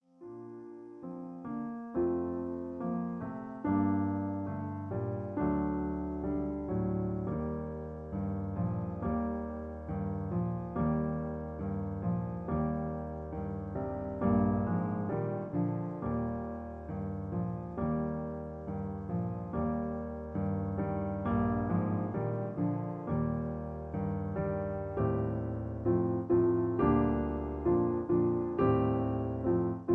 In B flat. Piano Accompaniment